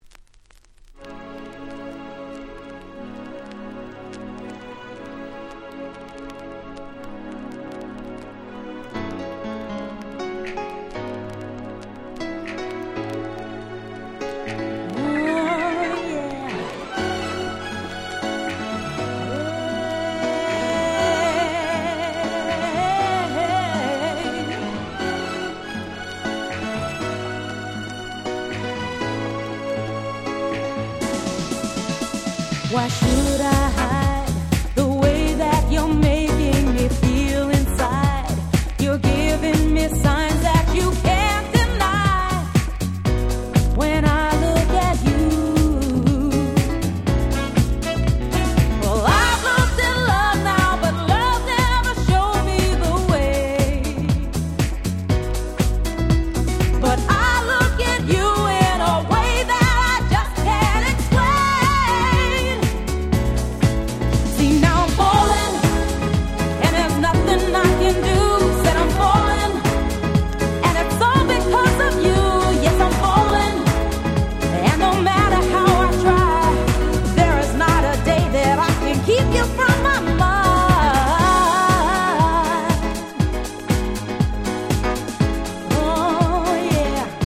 93' Nice R&B Album !!
90's キャッチー系 ボーカルハウス